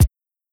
Kick Groovin 2.wav